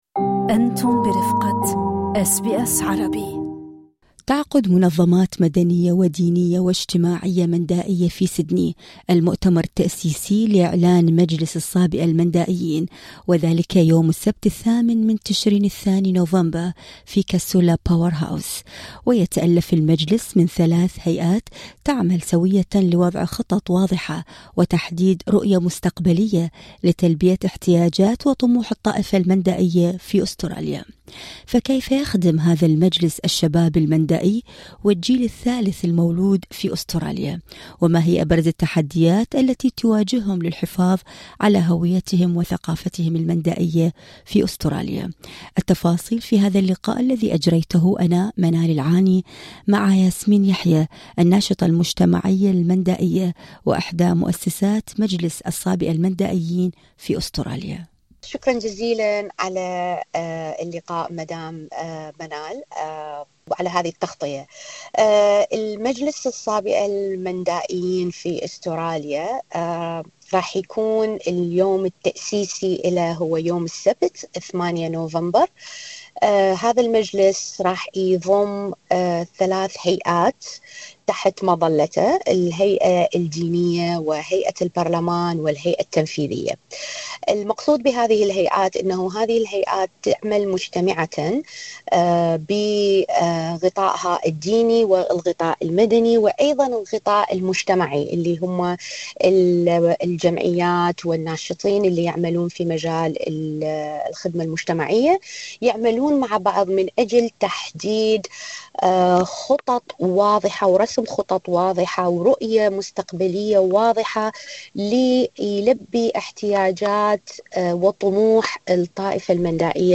التفاصيل في اللقاء الصوتي اعلاه